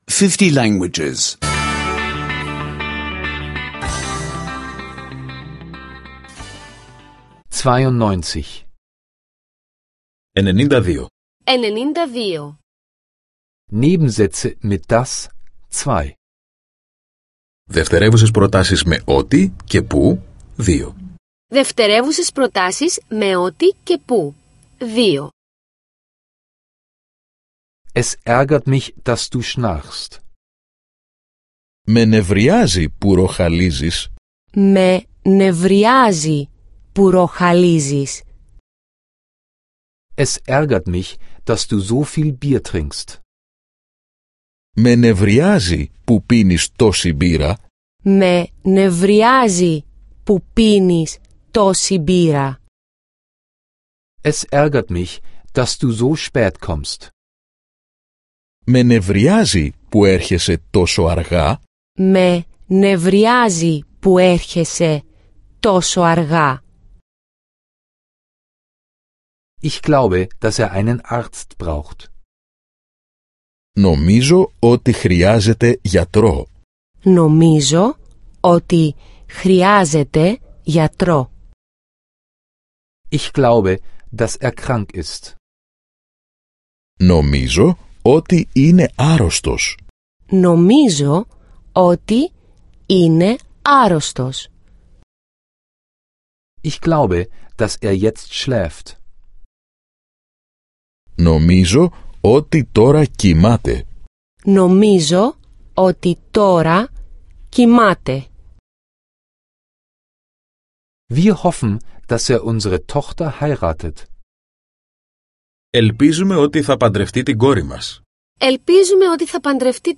Griechisch Audio-Lektionen, die Sie kostenlos online anhören können.